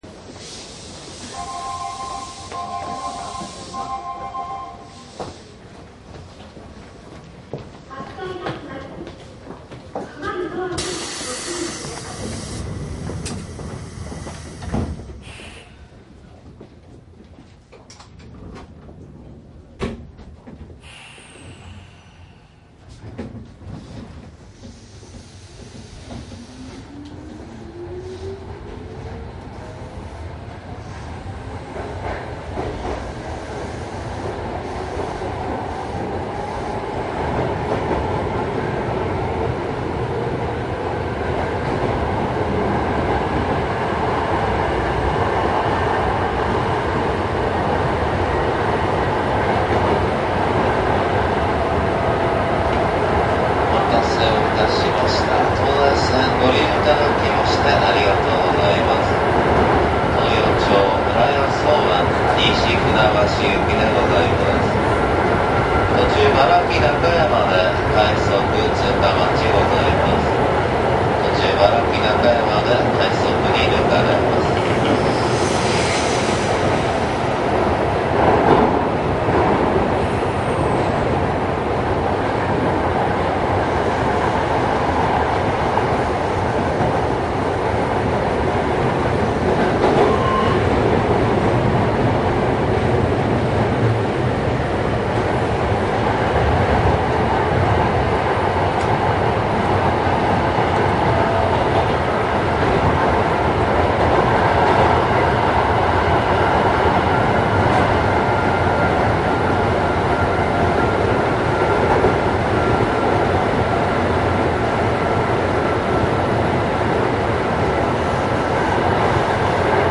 営団東西線 各駅停車走行音
西船橋方面にて営団5000系で録音しました。
モーター音は近鉄8000系など抵抗制御の通勤車とよく似ています。
録音車両：5248
マイクECM959です。MZRH1やDATの通常SPモードで録音。
実際に乗客が居る車内で録音しています。貸切ではありませんので乗客の会話やが全くないわけではありません。